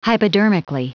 Prononciation du mot : hypodermically
hypodermically.wav